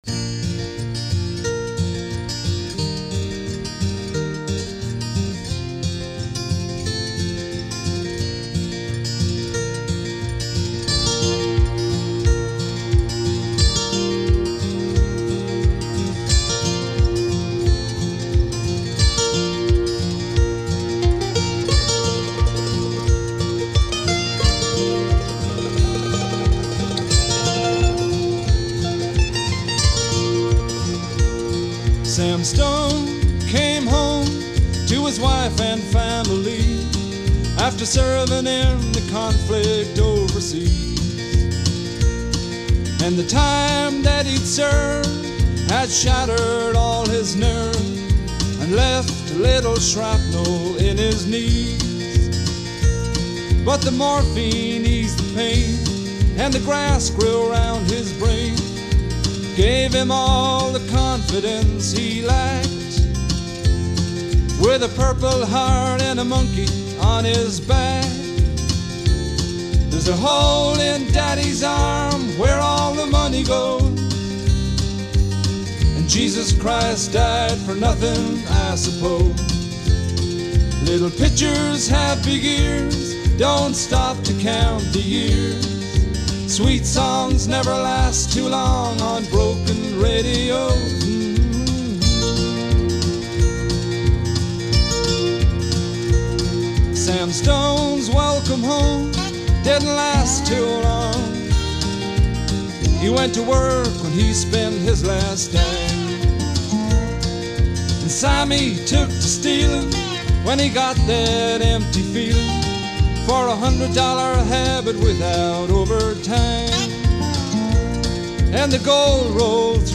American Bluegrass cross-over into country folk.